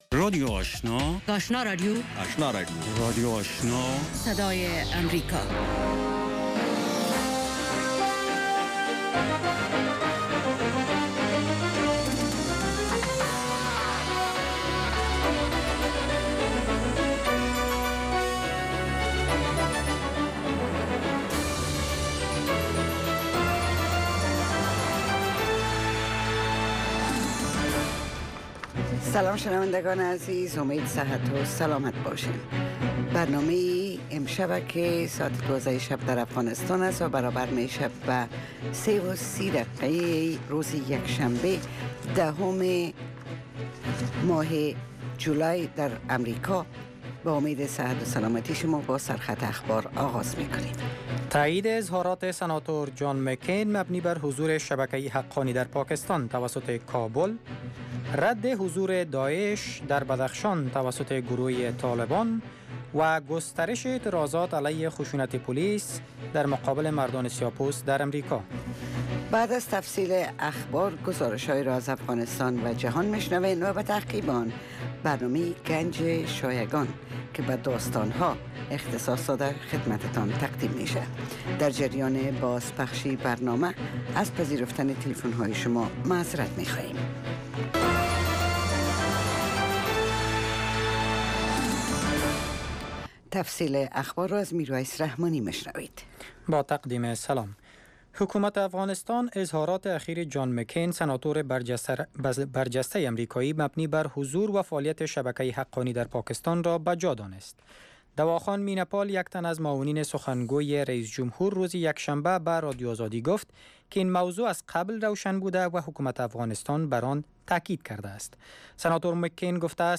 در این بخش ۱۰ دقیقه خبرهای تازه افغانستان و جهان نشر می شود.